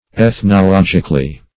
Meaning of ethnologically. ethnologically synonyms, pronunciation, spelling and more from Free Dictionary.
Search Result for " ethnologically" : The Collaborative International Dictionary of English v.0.48: Ethnologically \Eth`no*log"ic*al*ly\, adv.